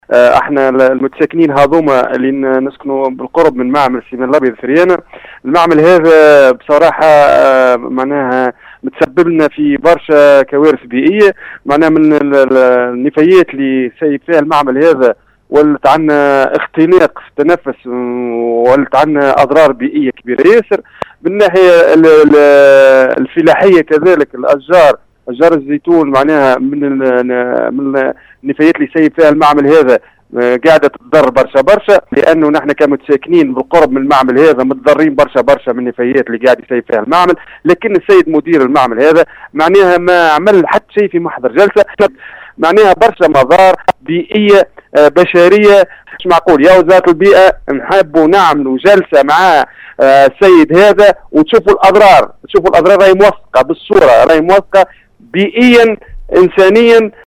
و في تصريح خص به راديو السيليوم اف ام قال احد المتضررين ان الفضلات التي يفرزها المعمل تعود بالضرر على الاهالي من خلال تدمير محاصيلهم اضافة الى الخطر الصحي المتمثل في عدة امراض تنفسية